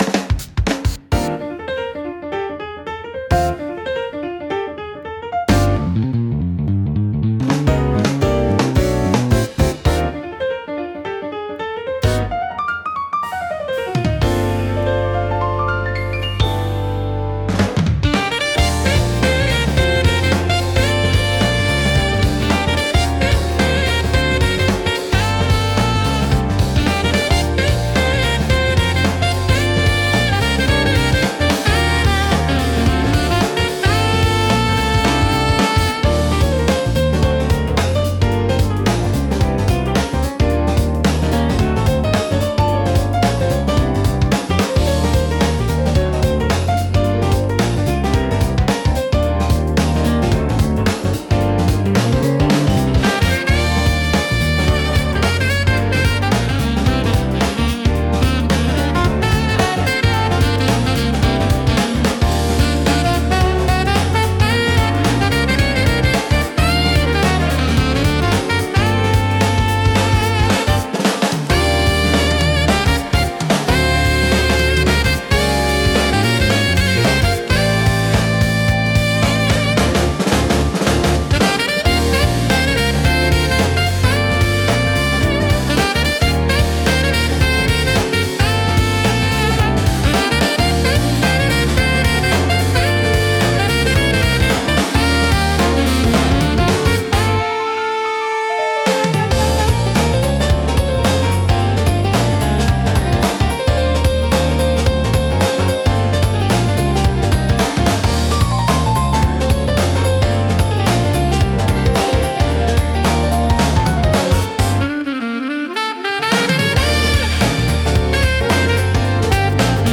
落ち着いた空気感を作り出しつつも、聴き疲れしにくい快適さがあり、ゆったりとした時間を楽しみたいシーンで多く活用されます。